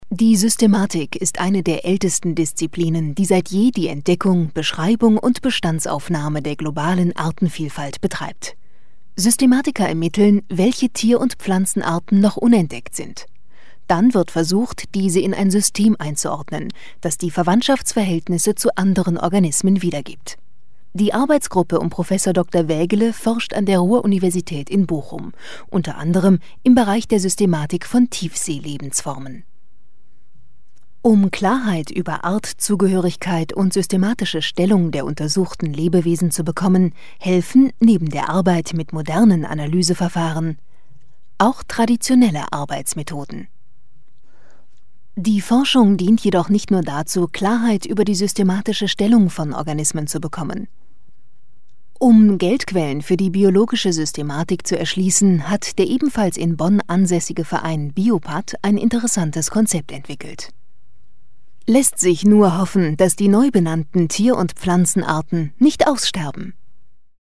Vielseitige Profi-Sprecherin deutsch: Werbung, TV-Trailer und voice over für VOX, Phoenix.
Sprechprobe: eLearning (Muttersprache):
german female voice over artist.